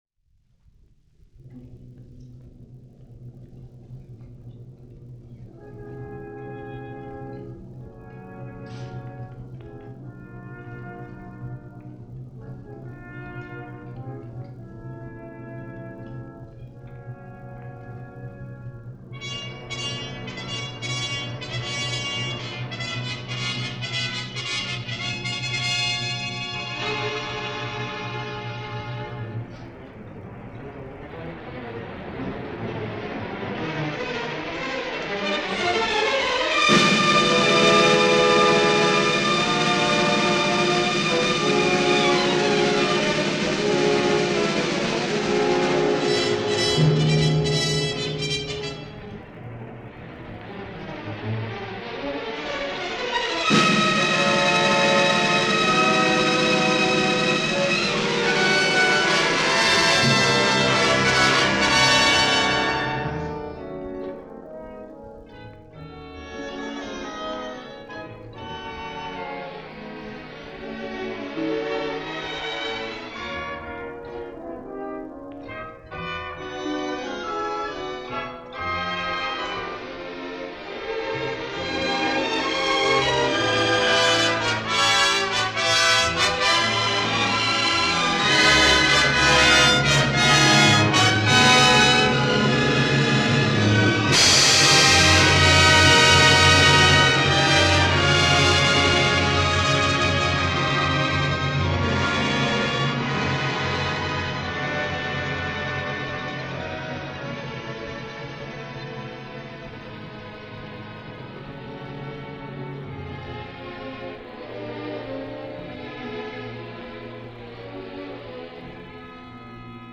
orchestral suite
from a radio broadcast